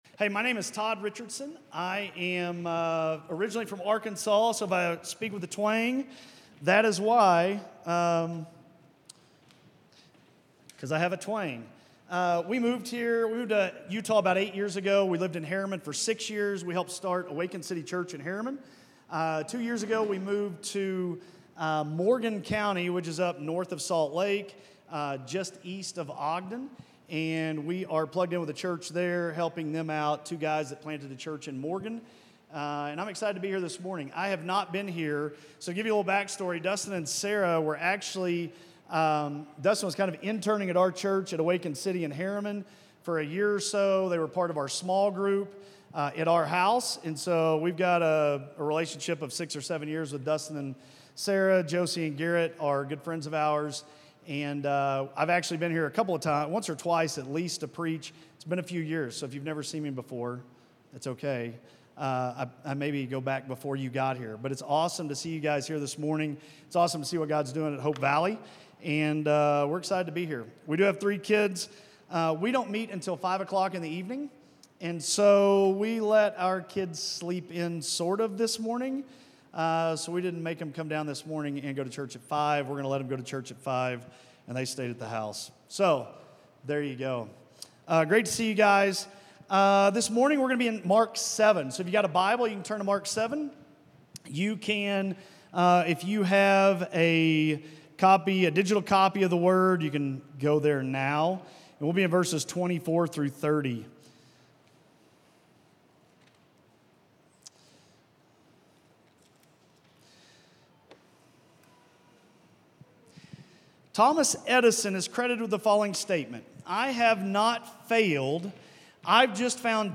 A message from the series "Stand-Alone Sermons."